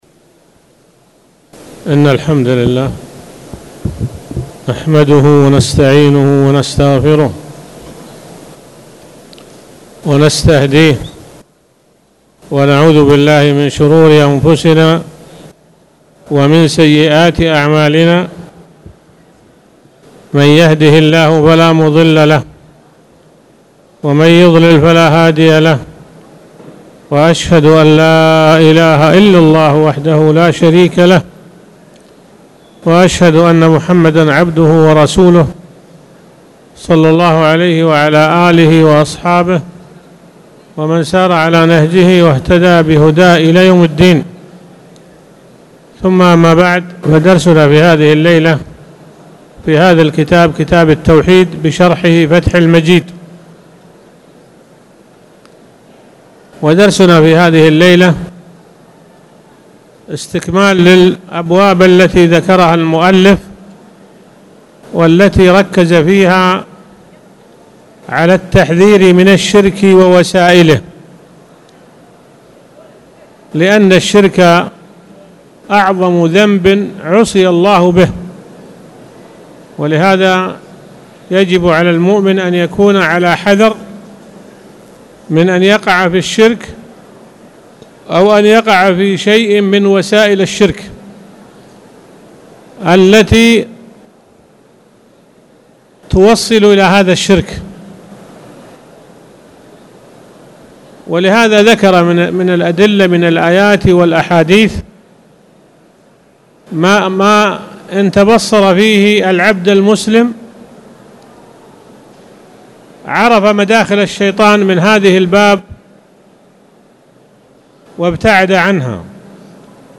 تاريخ النشر ٢٤ جمادى الأولى ١٤٣٨ هـ المكان: المسجد الحرام الشيخ